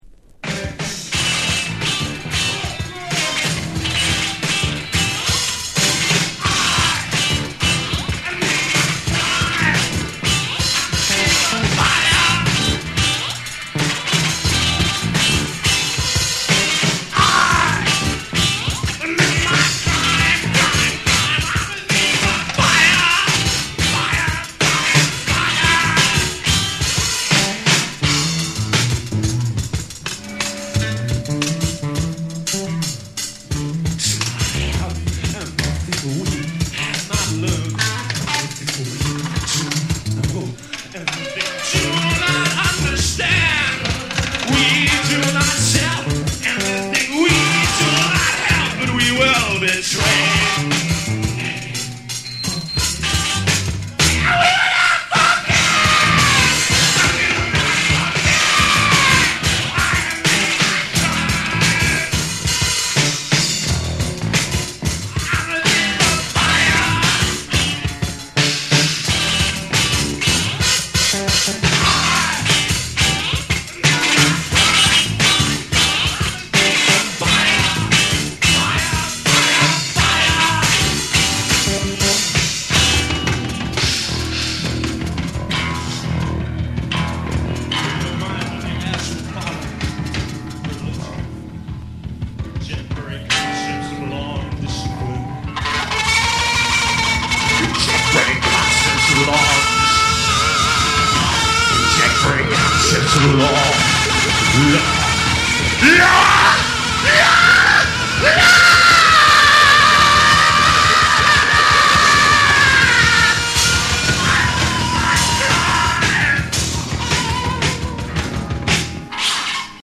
NEW WAVE & ROCK / REGGAE & DUB